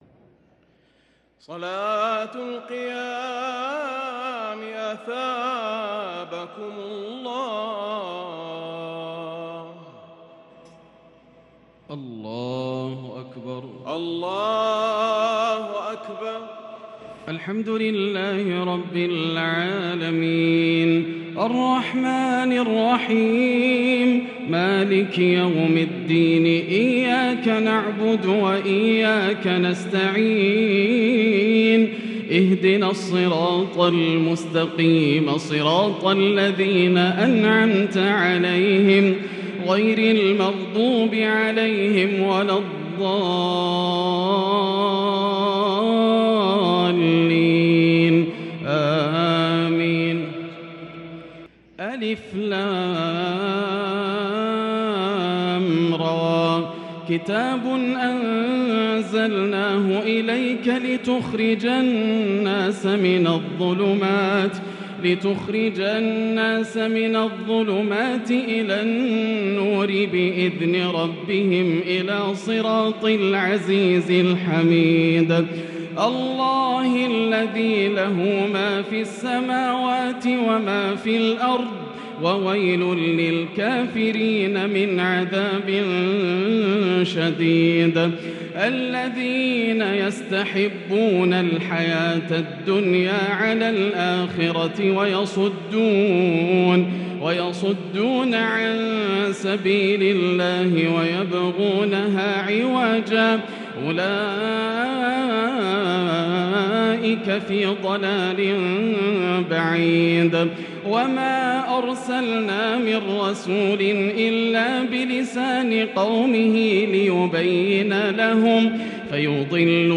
صلاة التهجد ليلة 29 رمضان 1443هـ | سورة إبراهيم كاملة | tahajud 29st night Ramadan 1443H Surah Ibrahim > تراويح الحرم المكي عام 1443 🕋 > التراويح - تلاوات الحرمين